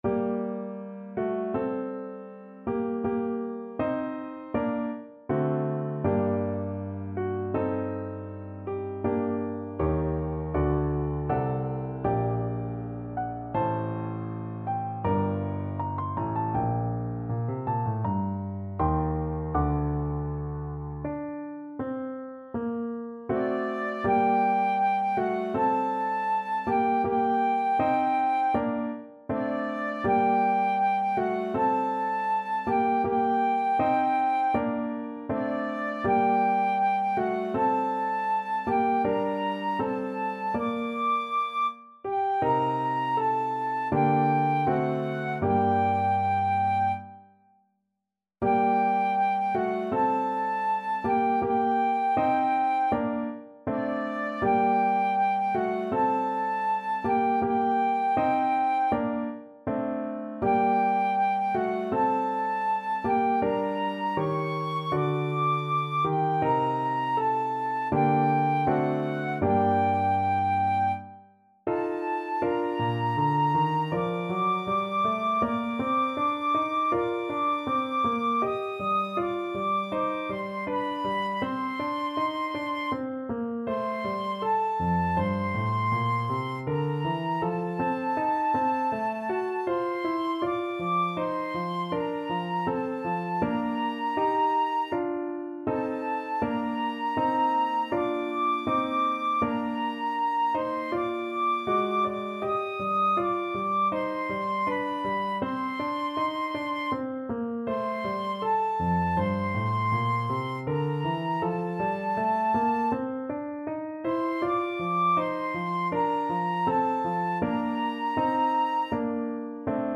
Flute
Time Signature: 4/4
Tempo Marking: Andante =c.80
Range: D6-Eb7 Score Key: G minor (Sounding Pitch)
Style: Classical